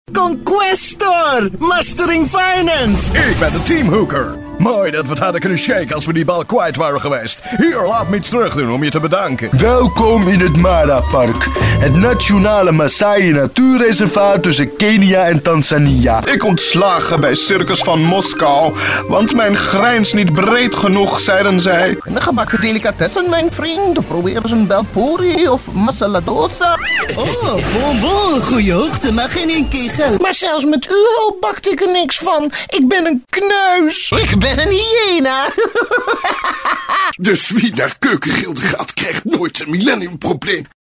My voice is frequently asked for business, hardselling, friendly and comical tone of voices.
My categories: Mid to low range, powerful, Authoritative, Bass-Baritone, Big, Deep Dynamic, Versatile, Dramatic, Contemporary, Intense, Raw, Flat, Monotone, Subdued, Real, Natural, Hard-Sell, Professional, Corporate, Conversational, Guy-Next-Door Bright, Warm, Clean, Crisp, Clear, Energetic, Fresh, Comedy, Cute, Goofy, Character, Wacky, Cartoon, Maternal, Sarcastic, Genuine, Friendly, Fun, Confident, Enthusiastic, Easy Going, Pleasant, Friendly, Sincere, Knowledgeable, Compassionate, Believable, Intimate, Playful, Informative
Sprecher niederländisch, holländisch für Werbung, Imagefilme, Zeichentrick, DVD-Spiele etc.
Sprechprobe: Industrie (Muttersprache):